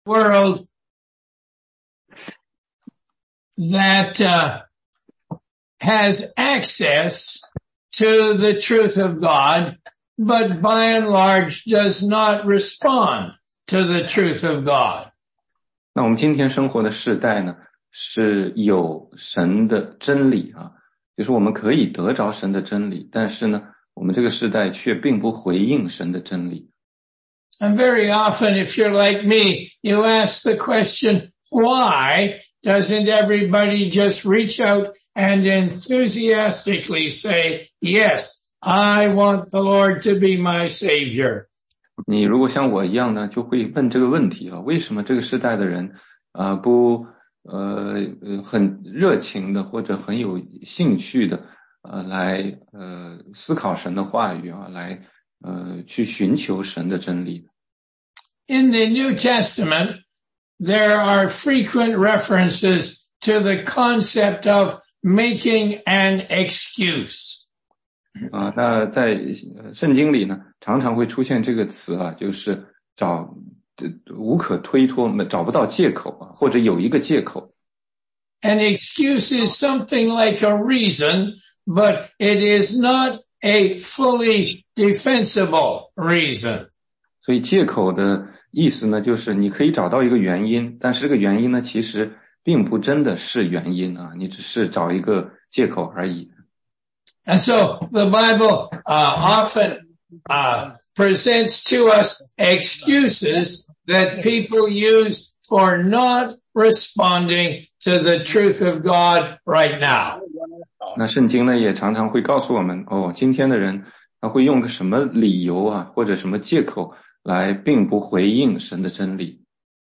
16街讲道录音 - 福音课第六十七讲（中英文）